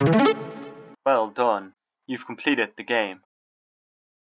End_Game_Victory_State
Game Game-Win Victory WAV sound effect free sound royalty free Gaming